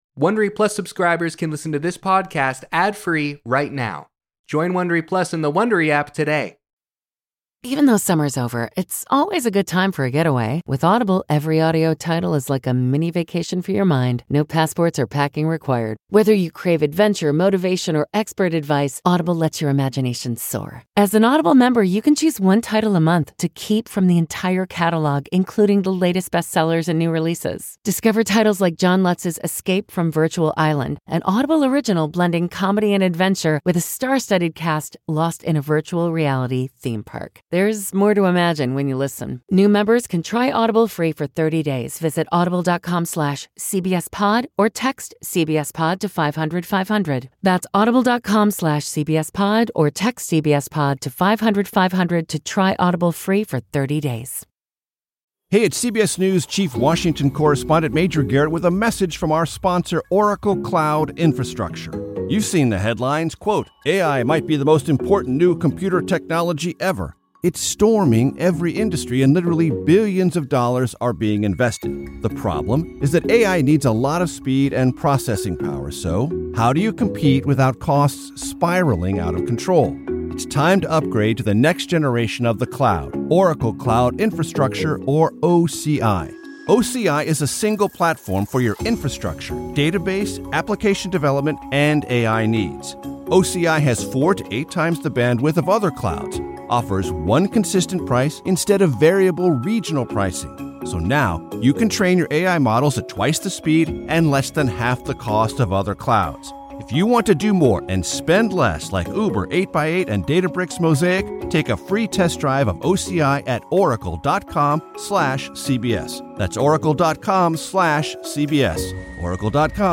Hosted by Jane Pauley. In our cover story, David Pogue looks at technology aimed at reducing collisions between whales and ships.